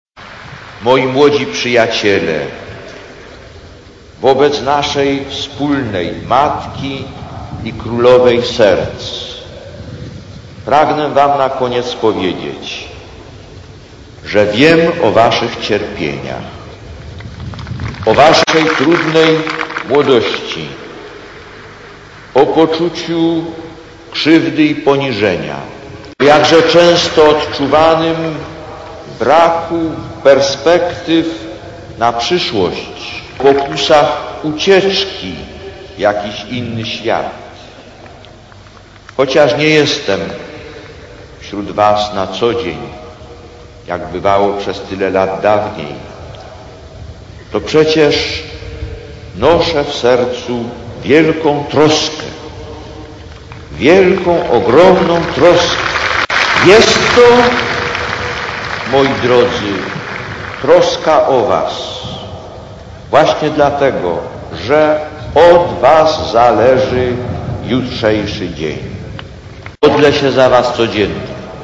Lektor: Z rozważania podczas Apelu jasnogórskiego (Częstochowa, 18 czerwca 1983 – nagranie): „Jest rzeczą niesłychanie doniosłą, aby w młodości - w tym wieku, w którym budzą się nowe uczucia miłości, uczucia decydujące nieraz o całym życiu - chodzić z takim dojrzałym wewnętrznym programem miłości, właśnie takim, o jakim mówi Apel Jasnogórski.